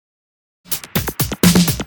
Fill 128 BPM (9).wav